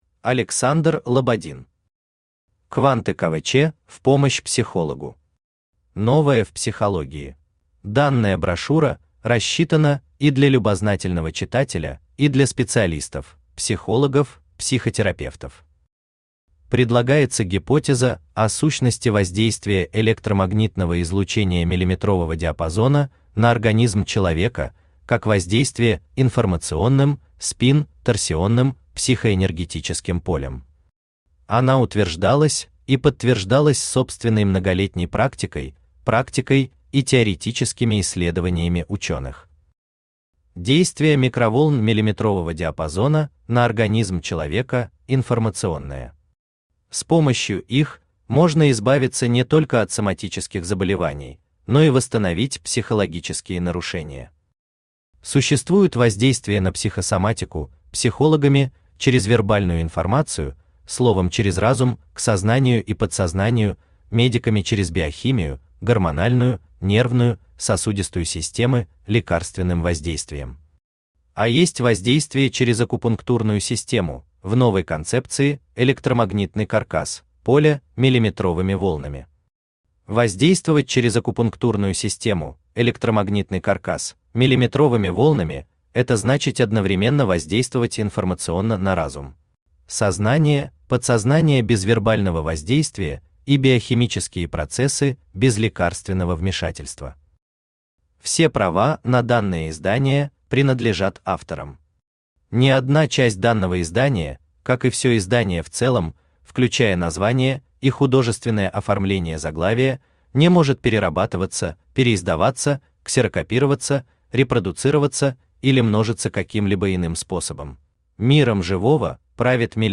Аудиокнига Кванты КВЧ в помощь психологу. Новое в психологии | Библиотека аудиокниг
Новое в психологии Автор Александр Петрович Лободин Читает аудиокнигу Авточтец ЛитРес.